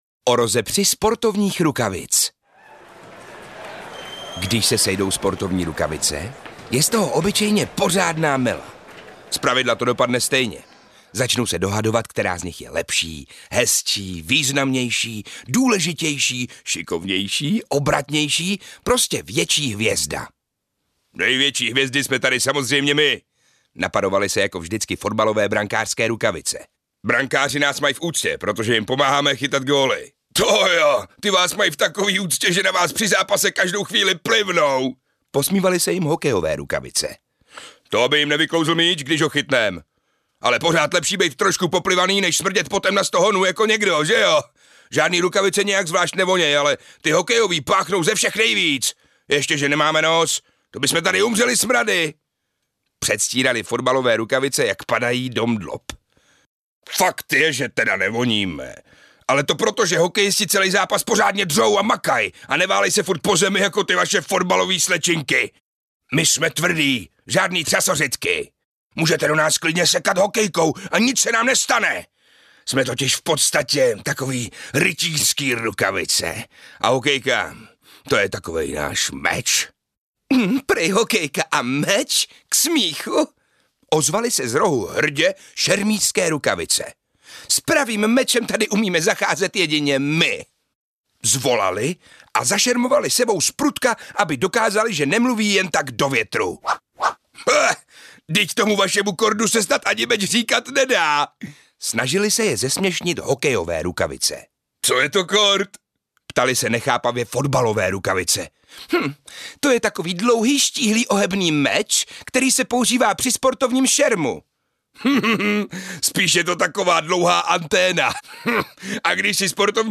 Nedáš, dostaneš! audiokniha
Ukázka z knihy